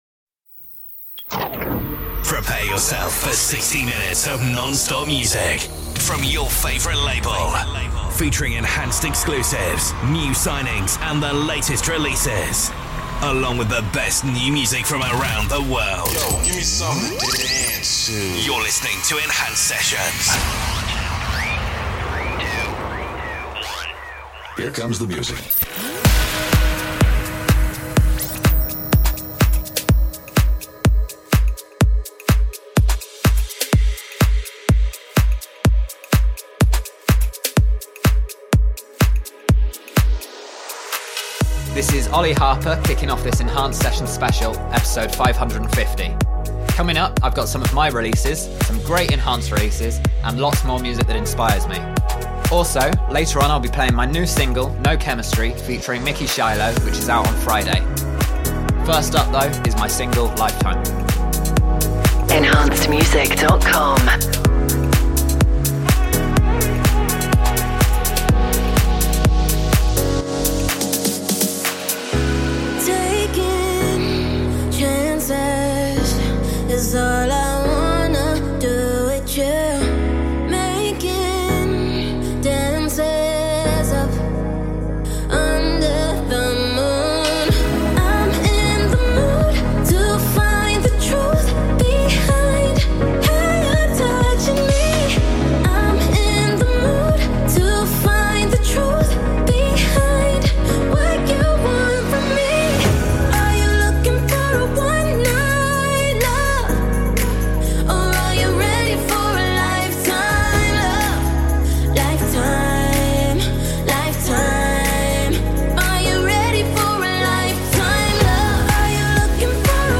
Also find other EDM Livesets, DJ Mixes and Radio
Liveset/DJ mix